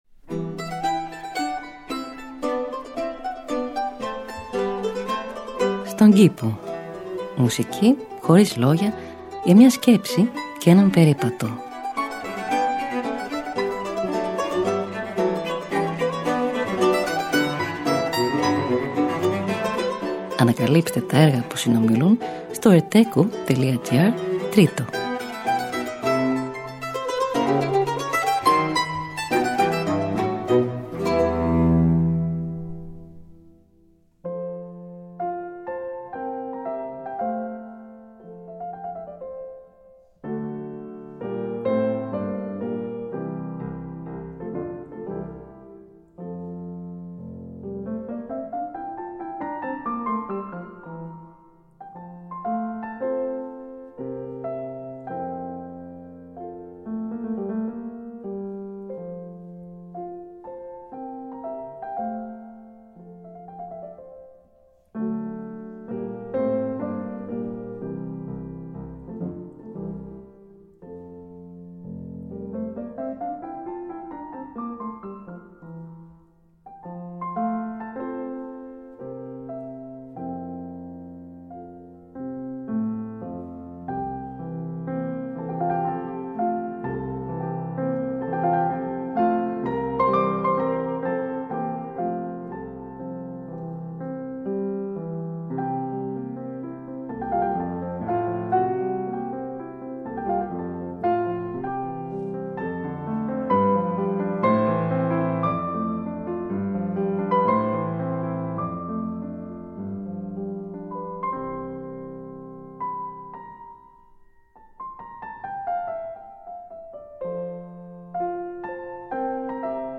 Μουσική Χωρίς Λόγια για μια Σκέψη και έναν Περίπατο.
Arrange for mandolin and continuo